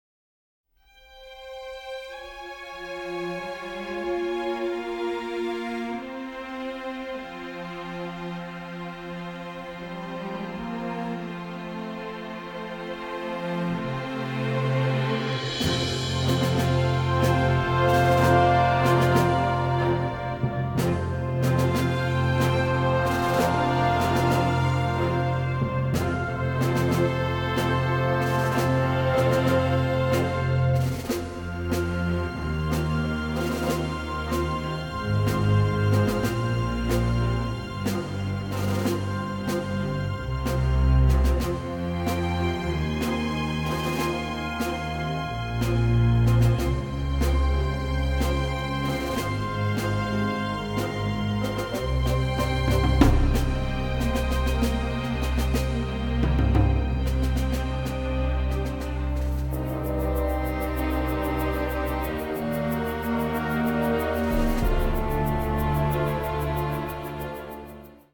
(Original Score)